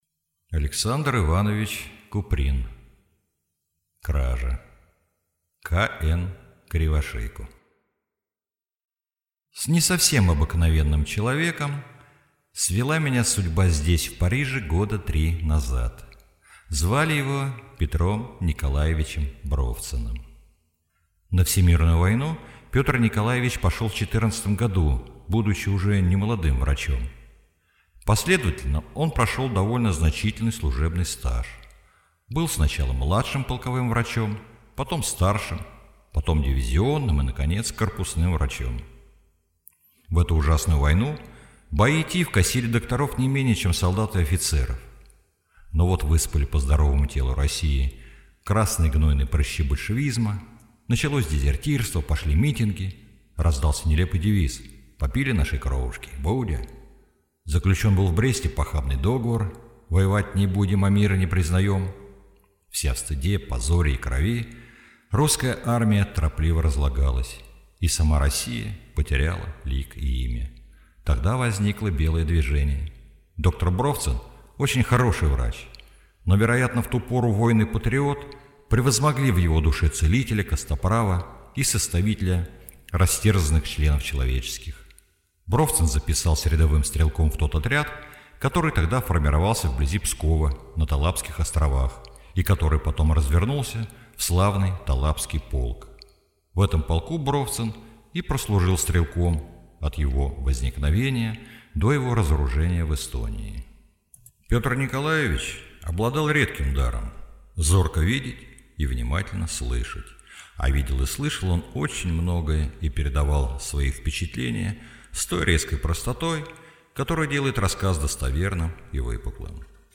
Аудиокнига Кража | Библиотека аудиокниг